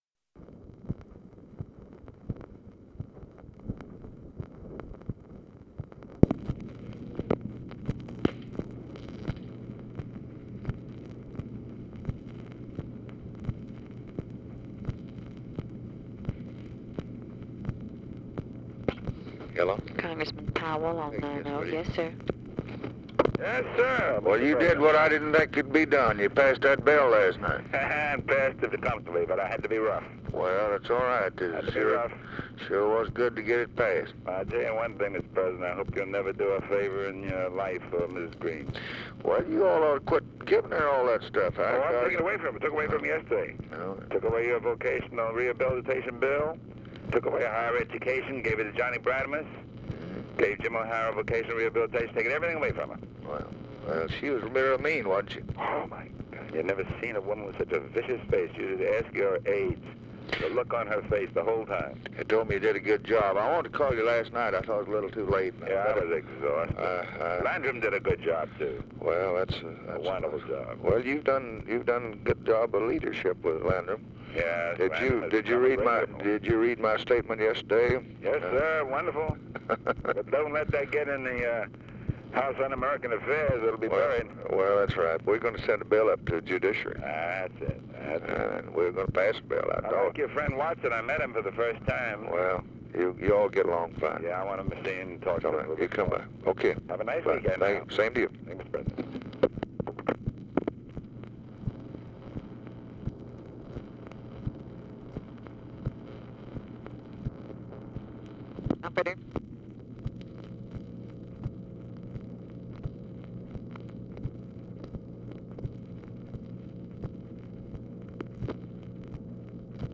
Conversation with ADAM CLAYTON POWELL, March 27, 1965
Secret White House Tapes